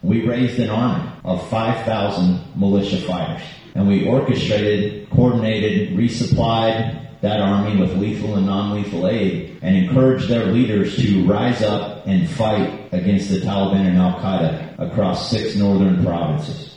Whiskey and War Stories: Former Green Beret tells the story of the “Horse Soldiers”
The Wamego Whiskey Syndicate hosted Whiskey and War Stories before a packed house at the Columbian Theatre in Wamego last night.